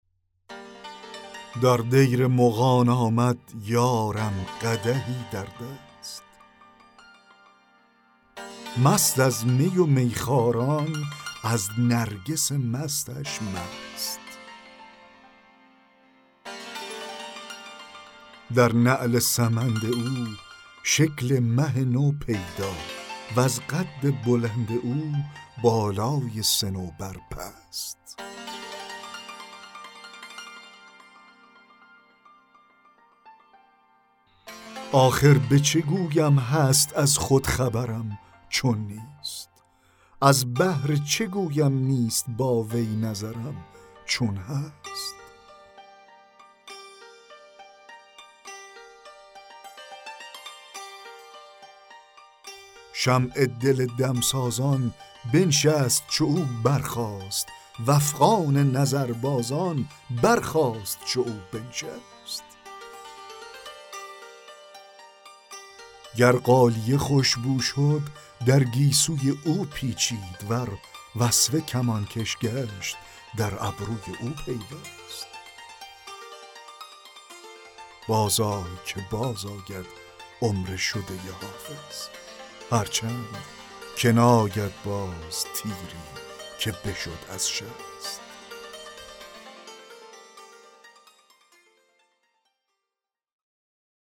دکلمه غزل 27 حافظ
دکلمه-غزل-27-حافظ-در-دیر-مغان-آمد-یارم-قدحی-در-دست.mp3